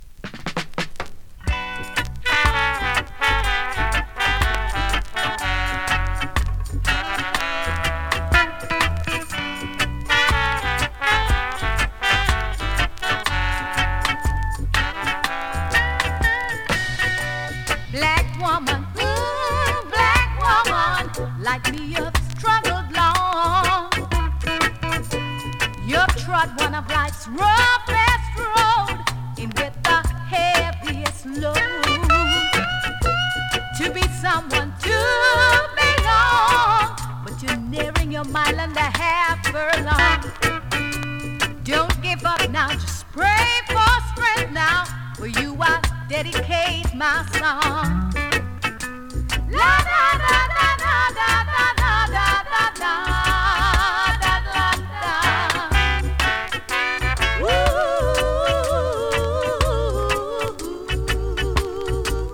2016 NEW IN!!SKA〜REGGAE!!
スリキズ、ノイズ比較的少なめで